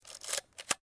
Photo_instamatic.ogg